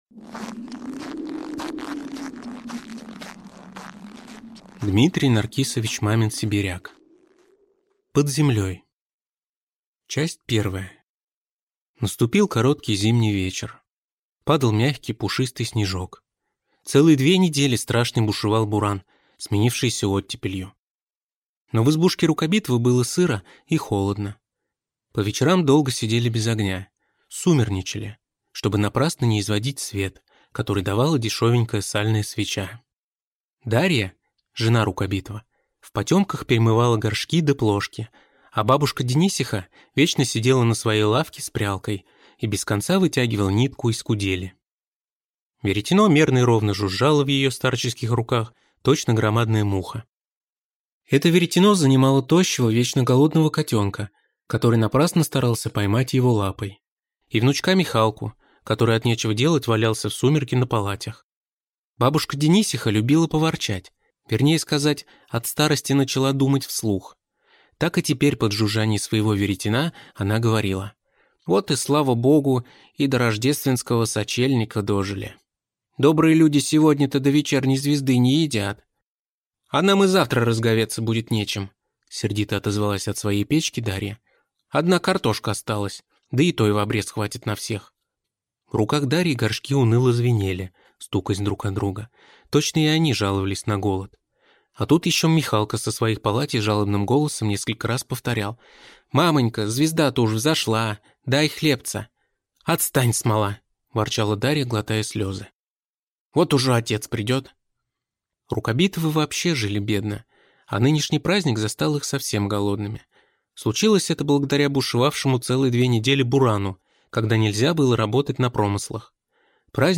Аудиокнига Под землей | Библиотека аудиокниг